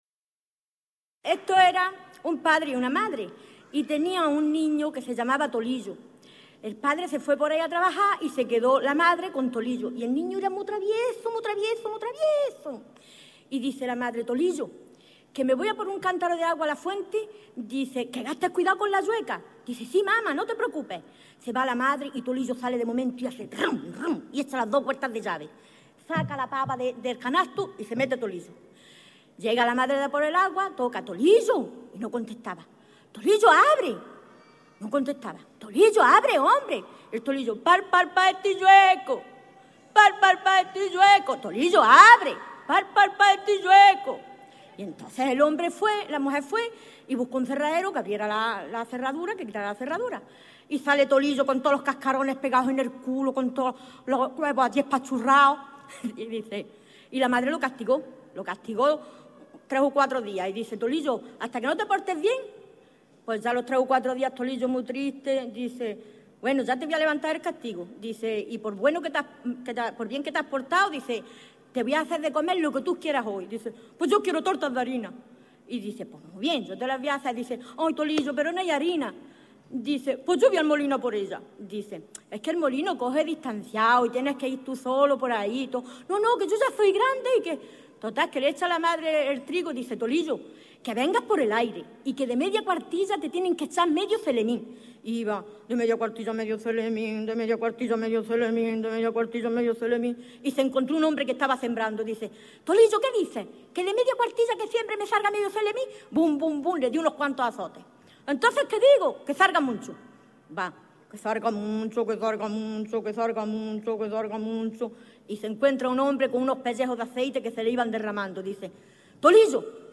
Registros relacionados: En: 1er Certamen Narración Oral; 2º Semana de la Oralidad (jun.-oct. 2001).
Materia / geográfico / evento: Cuentos populares Icono con lupa
Secciones - Biblioteca de Voces - Cultura oral